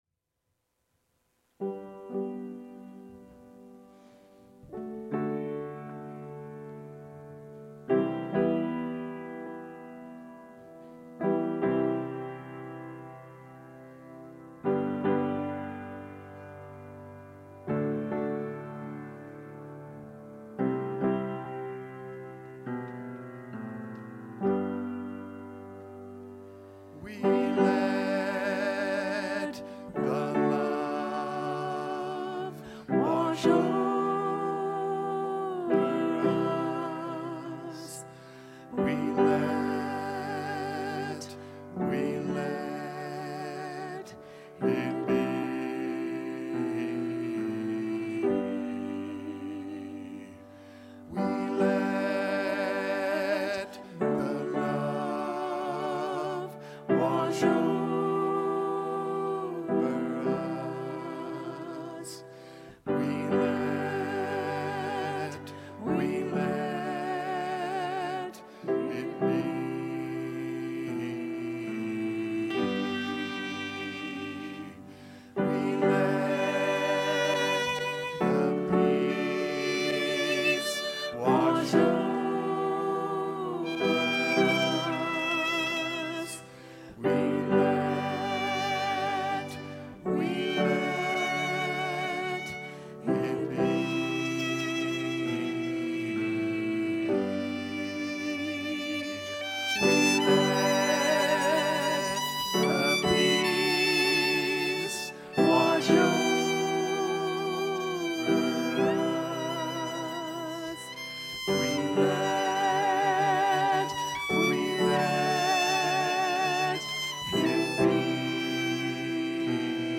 The audio recording (below the video clip) is an abbreviation of the service. It includes the Meditation, Lesson, and Featured Song.